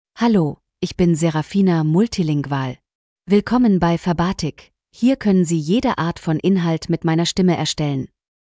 Seraphina MultilingualFemale German AI voice
Seraphina Multilingual is a female AI voice for German (Germany).
Voice sample
Listen to Seraphina Multilingual's female German voice.
Female